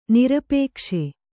♪ nirapēkṣe